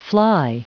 Prononciation du mot fly en anglais (fichier audio)
Prononciation du mot : fly